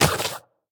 biter-roar-mid-5.ogg